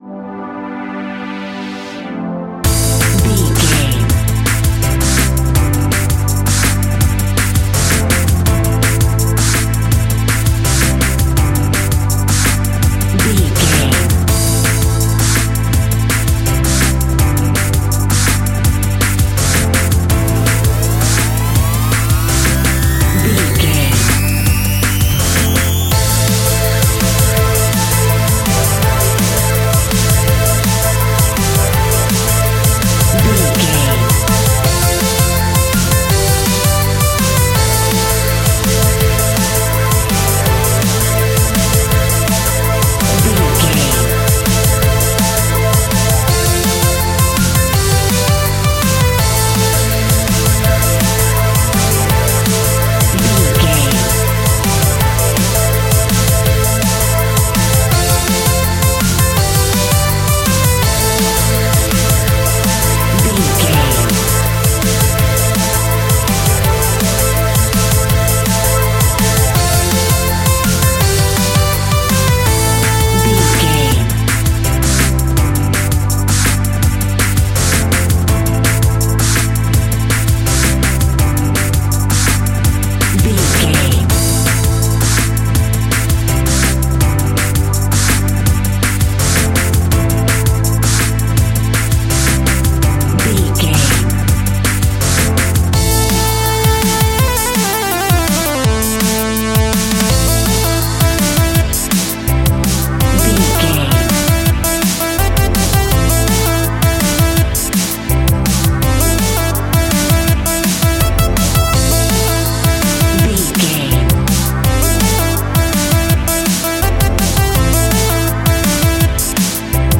Aeolian/Minor
Fast
frantic
driving
energetic
hypnotic
industrial
drum machine
synthesiser
Drum and bass
electronic
synth bass
synth lead
synth pad
robotic